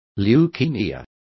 Complete with pronunciation of the translation of leukaemia.